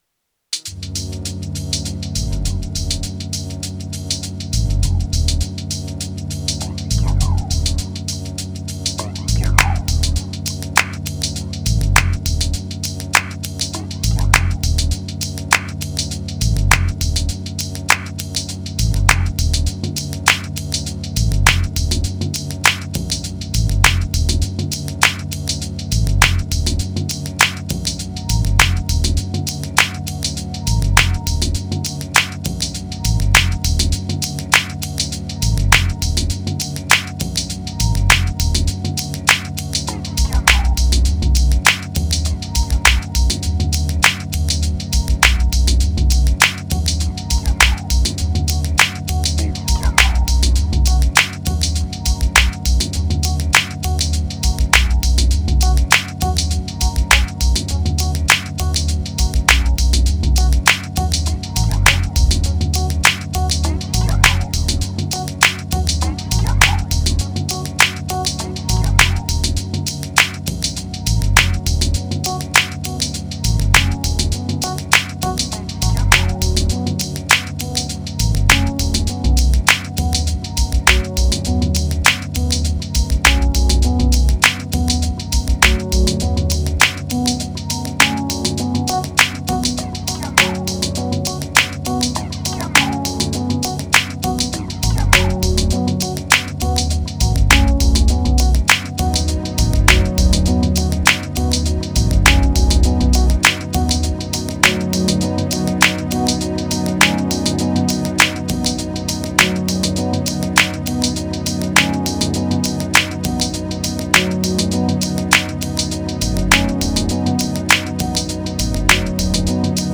878📈 - 79%🤔 - 101BPM🔊 - 2020-11-15📅 - 808🌟
Riddim Peace Triphop Chillout Groove Explorer Relief